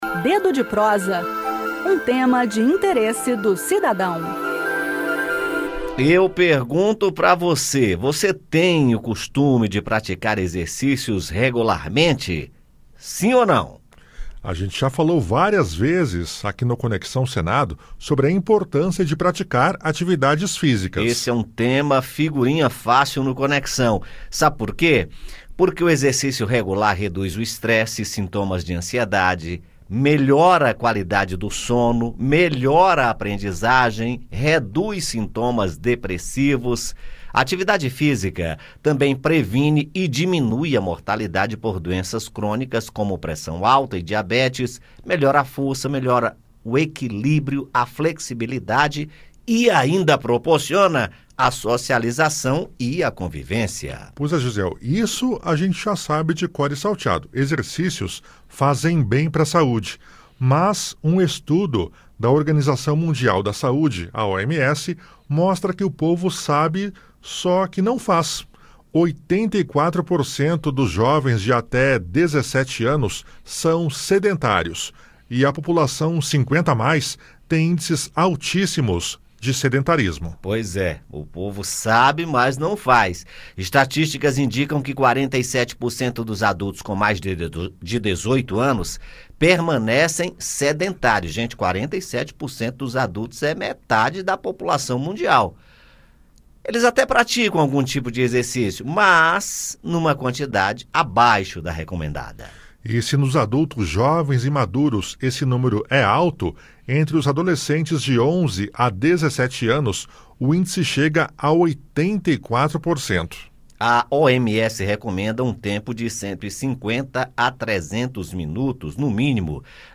A orientação é exercitar-se com regularidade para diminuir as chances de ter problemas associados ao sedentarismo. No bate-papo desta sexta-feira (08), ouça as recomendações para iniciar uma atividade física regular e manter a saúde e o bem-estar.